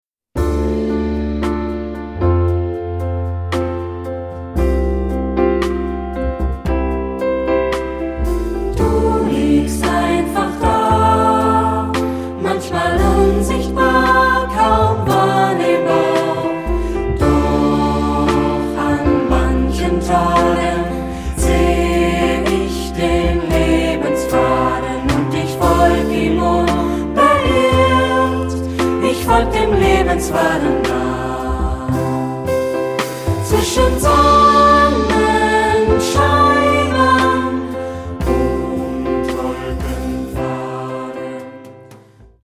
Tonart(en): d-moll